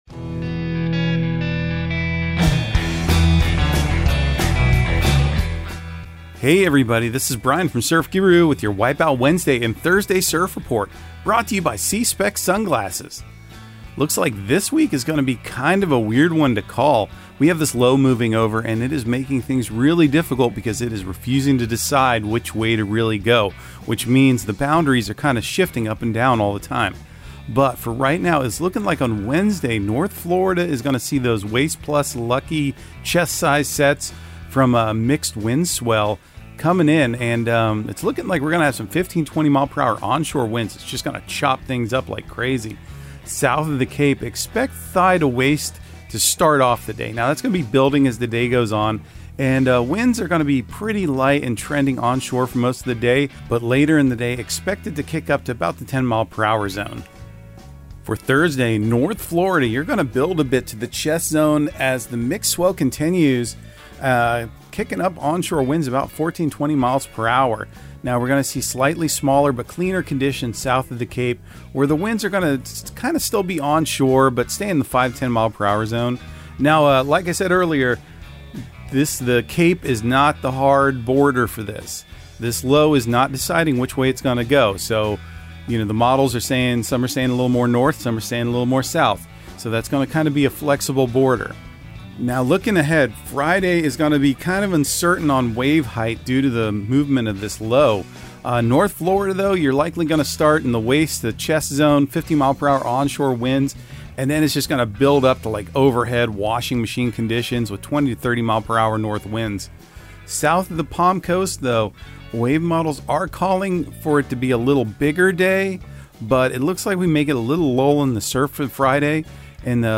Surf Guru Surf Report and Forecast 05/24/2023 Audio surf report and surf forecast on May 24 for Central Florida and the Southeast.